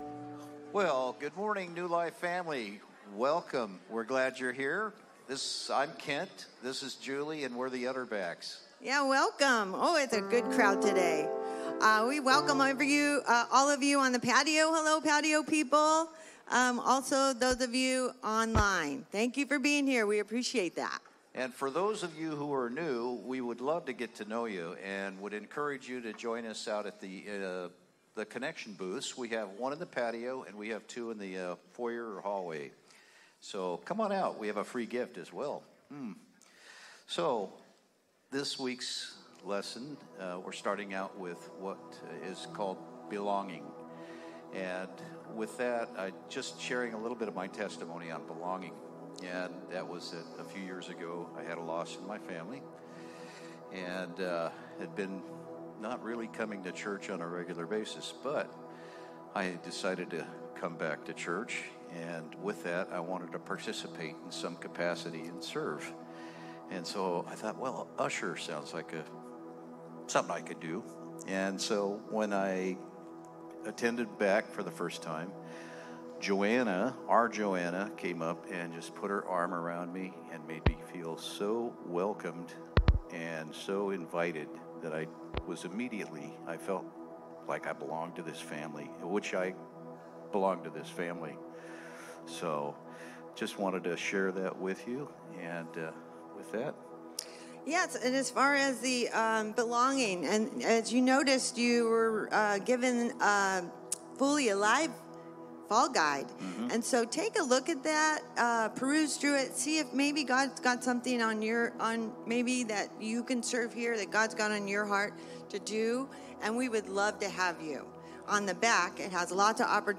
A message from the series "No Longer Strangers."